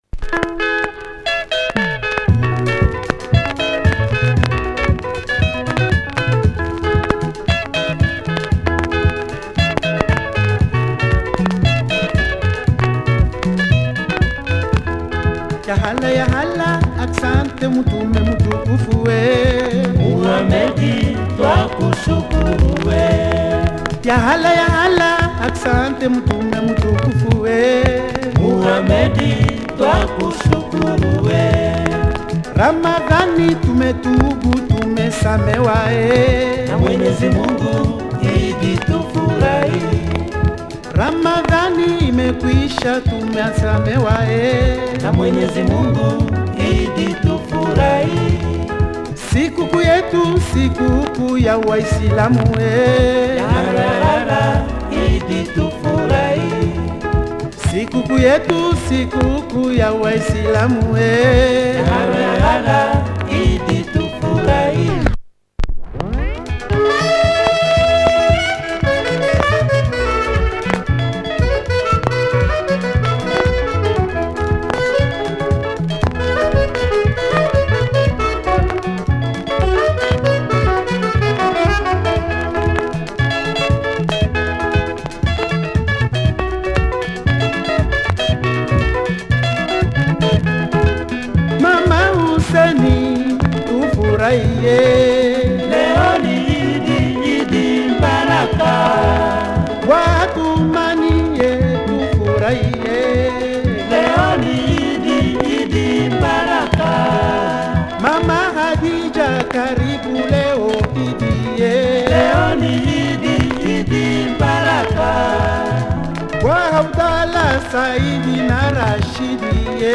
Catchy track
Marks & scuffs but plays.